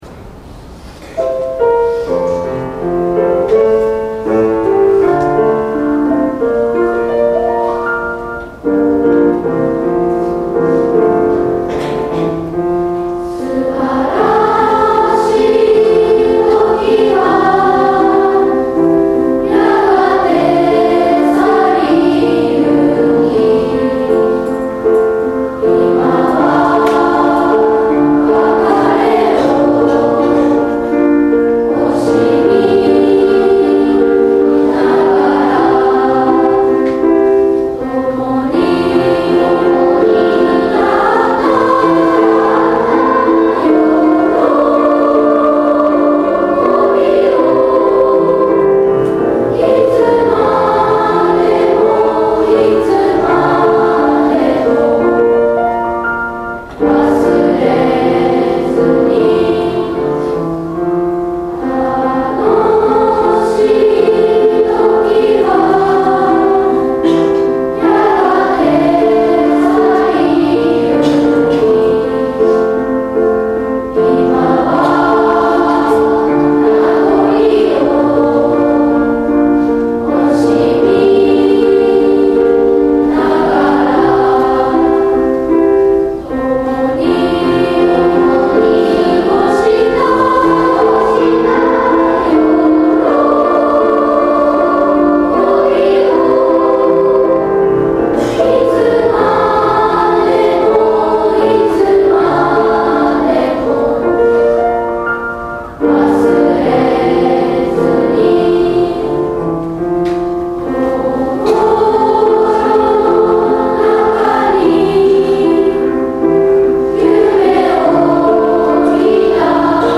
昨年度の卒業式の歌声です。